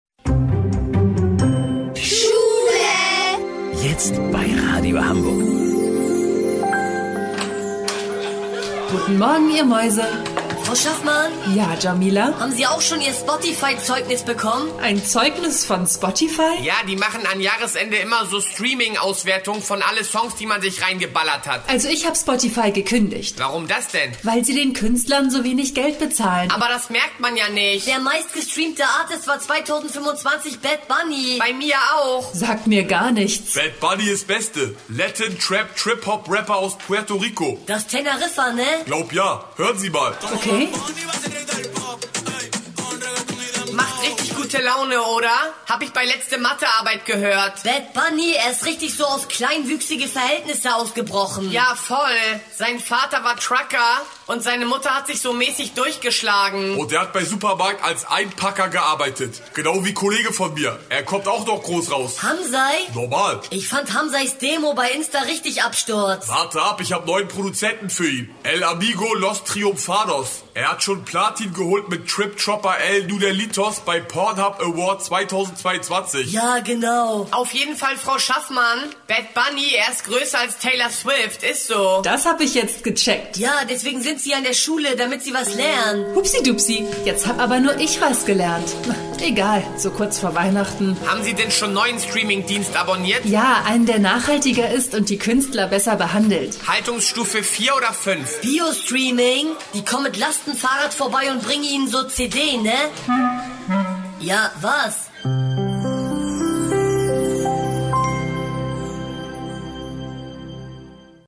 Comedy